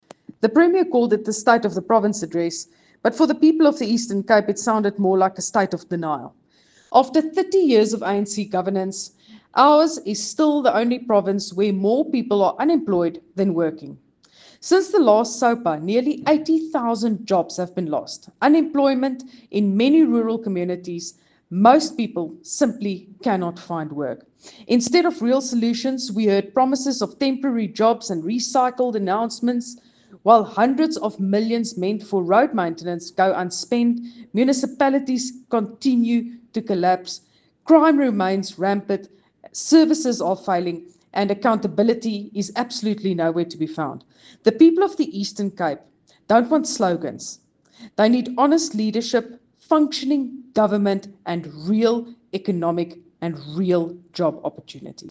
Afrikaans from Dr Vicky Knoetze MPL